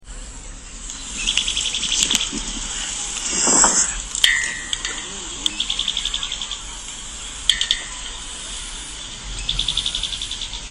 Mosqueta Estriada (Myiophobus fasciatus)
Nombre en inglés: Bran-colored Flycatcher
Condición: Silvestre
Certeza: Vocalización Grabada